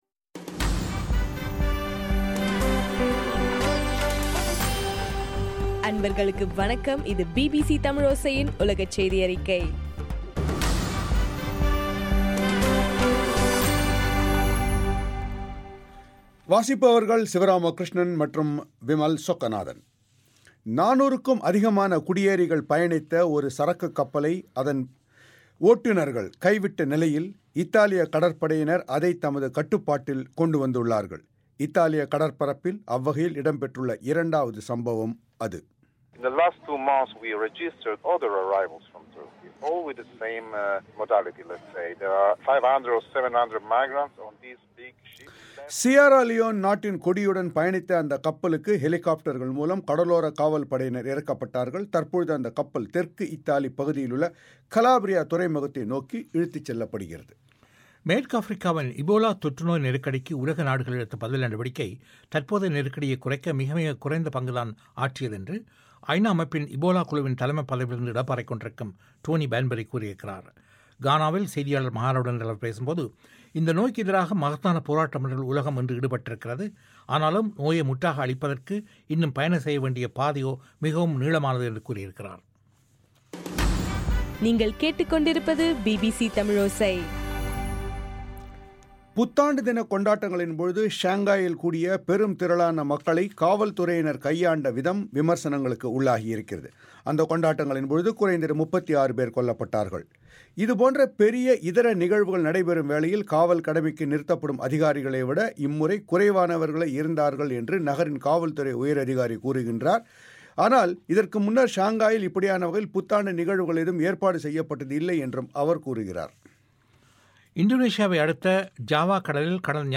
ஜனவரி 2 பிபிசியின் உலகச் செய்திகள்